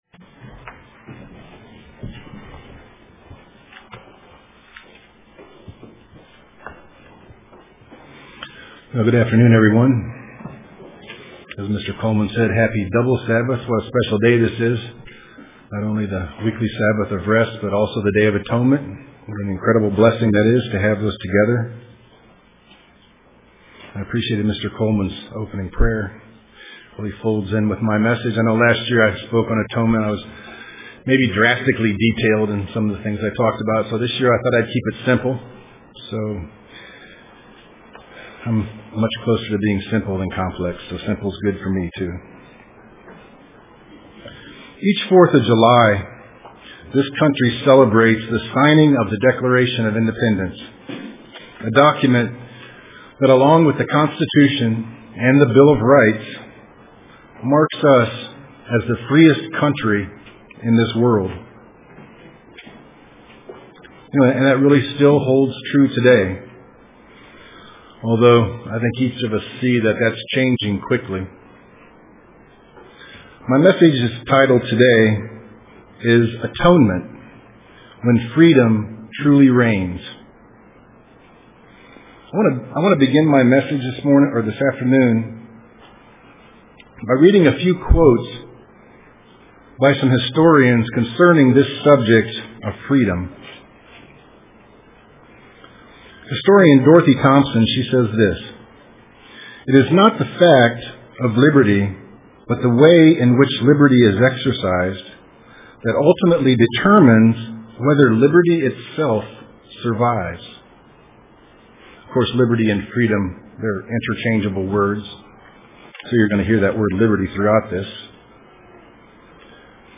Print Atonement, When Freedom Truly Reigns UCG Sermon Studying the bible?